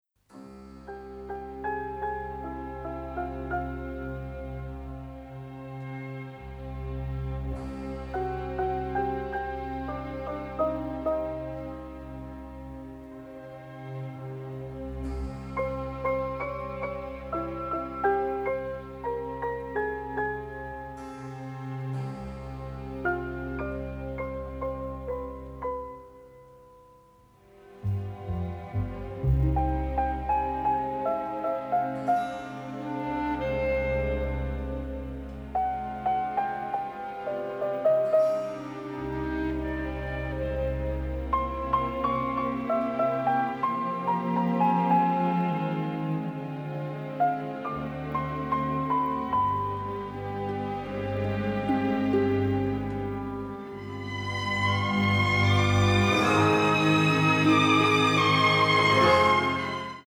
psychedelic cult classics